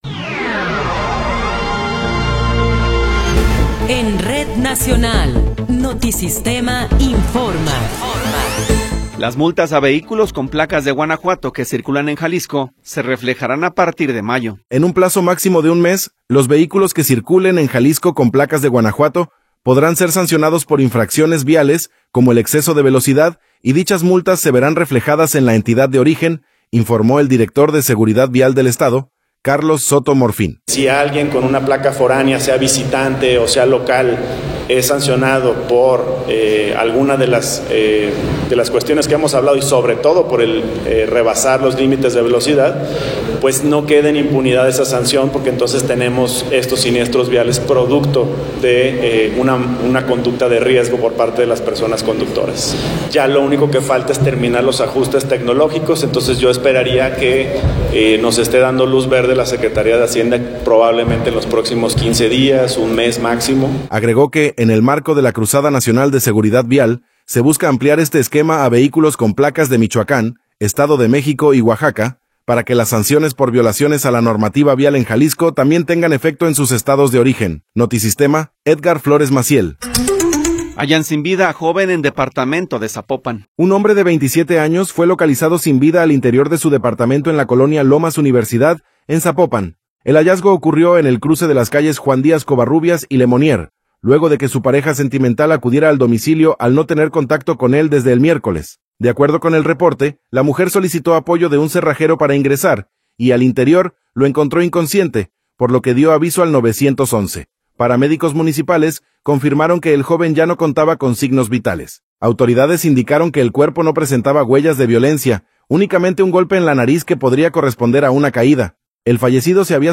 Noticiero 11 hrs. – 24 de Abril de 2026